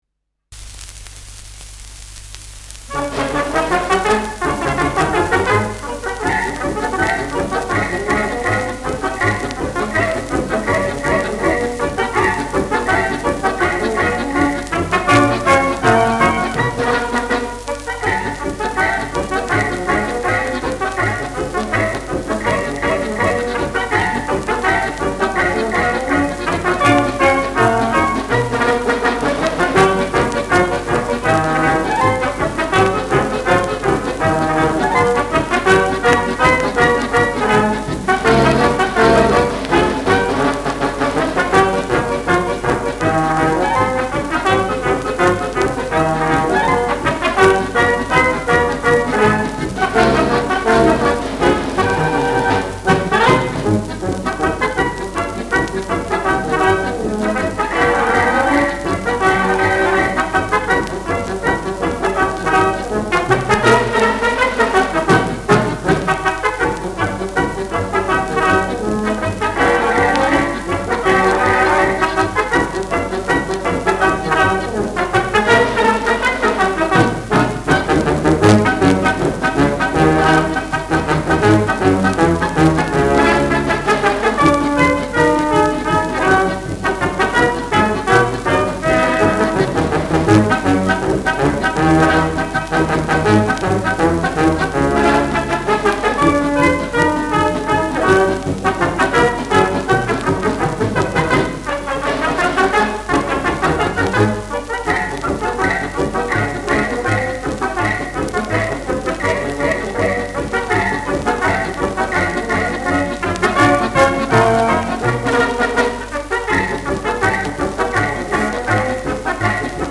Polka schnell
Schellackplatte
leichtes Rauschen : leichtes Knistern
mit Gesang